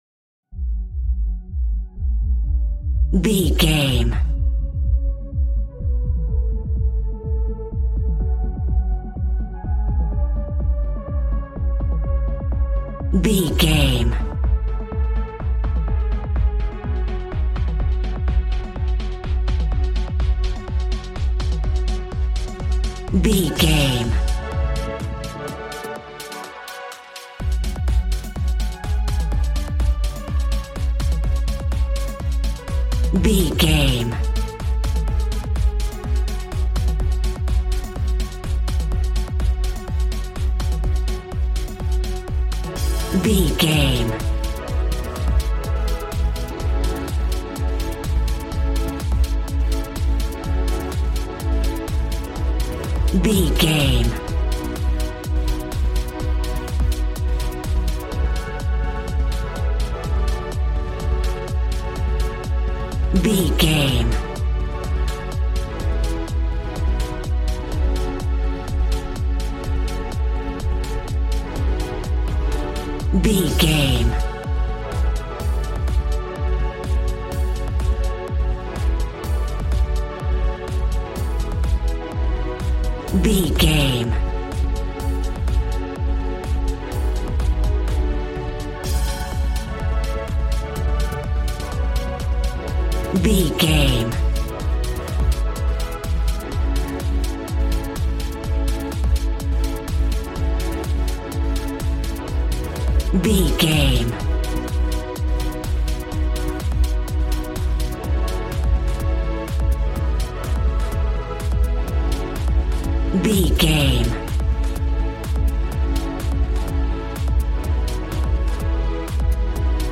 Aeolian/Minor
G♭
Fast
uplifting
lively
groovy
synthesiser
drums